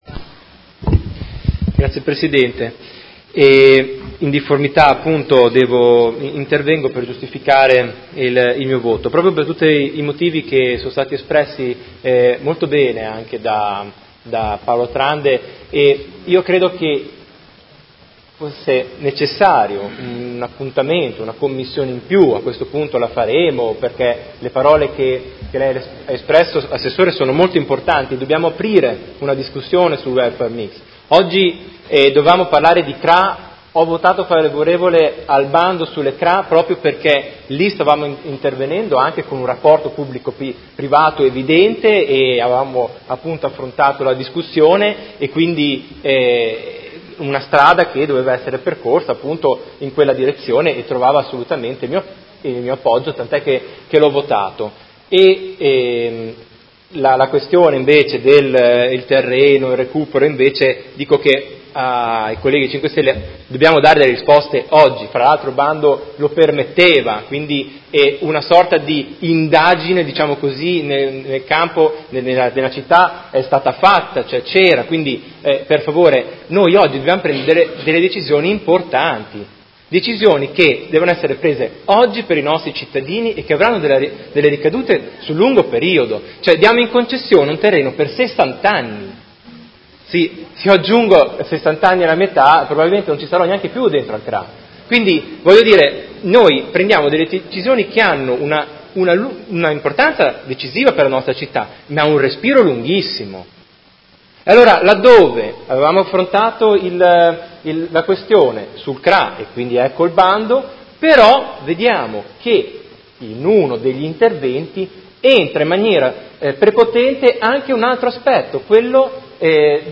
Marco Chincarini — Sito Audio Consiglio Comunale
Seduta del 18/10/2018 Dichiarazione di voto.